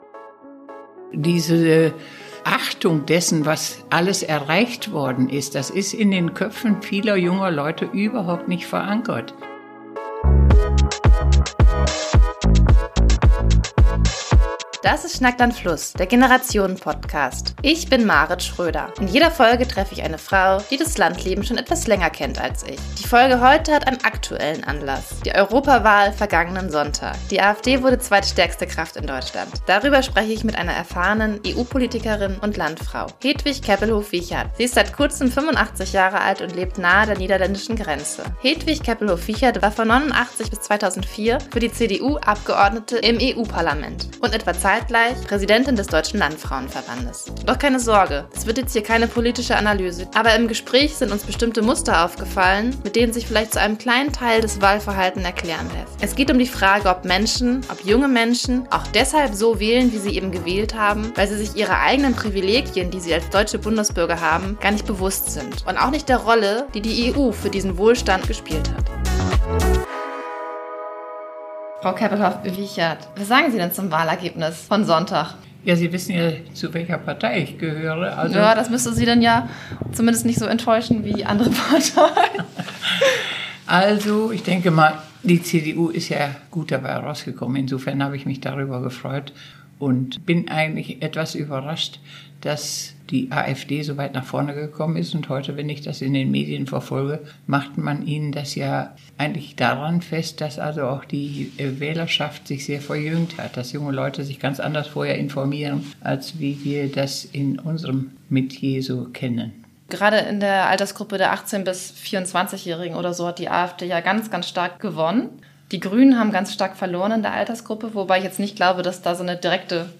Wie Angst um den Status quo unser Wahlverhalten beeinflusst - ein Gespräch mit Hedwig Keppelhoff-Wiechert